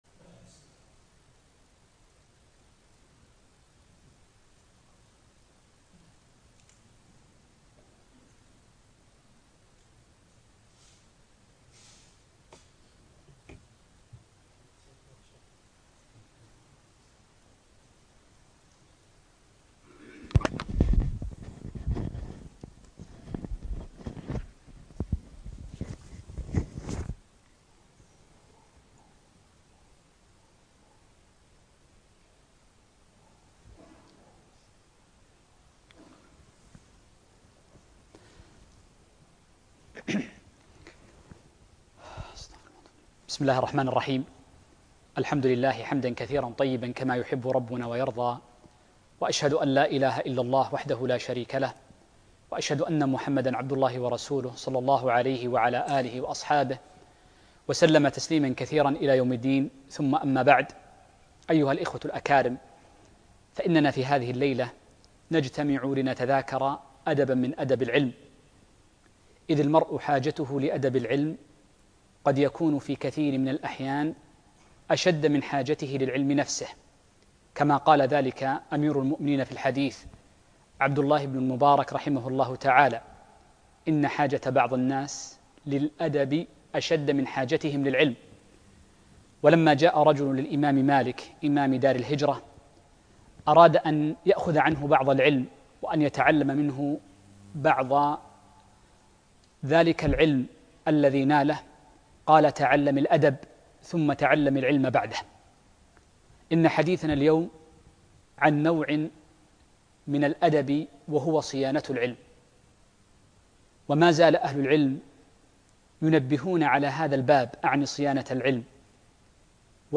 تعظيم العلم وصيانتة - محاضرة